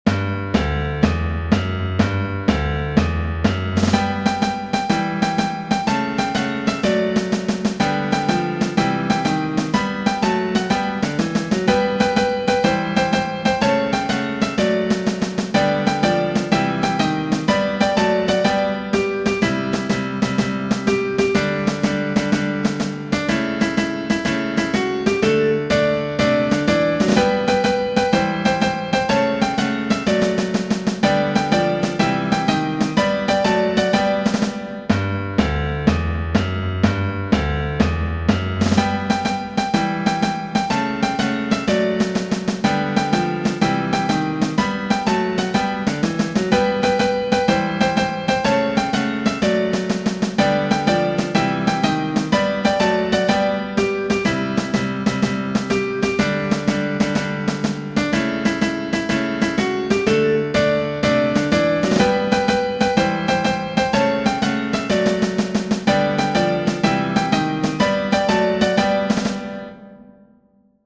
Genere: Bambini